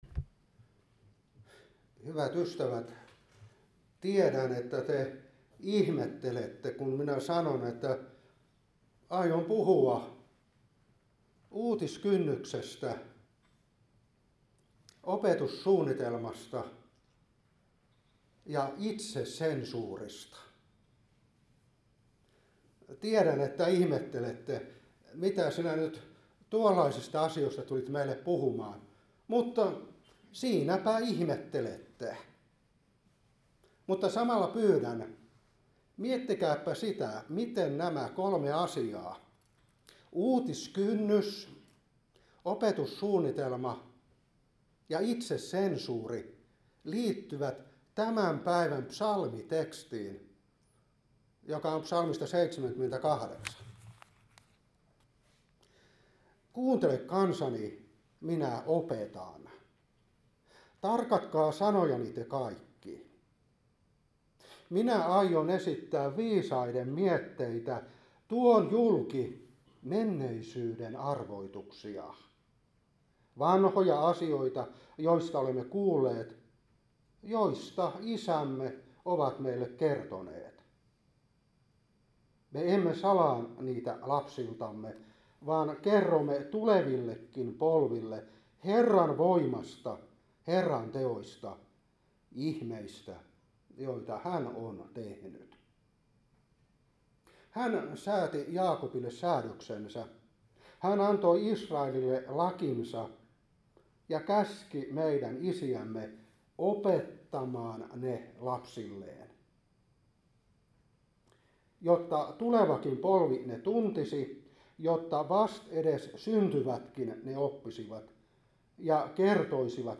Opetuspuhe 2025-10.